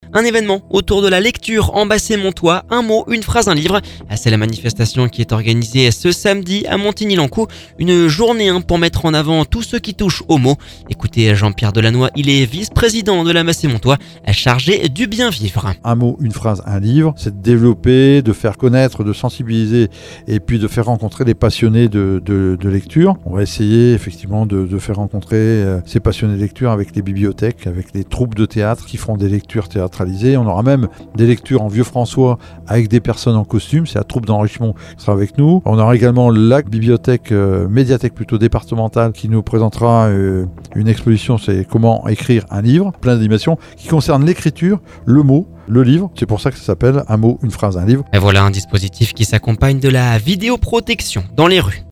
Jean-Pierre Delannoy, vice-président de la Bassée Montois chargé du bien vivre.